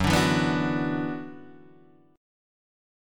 Gb7sus4#5 chord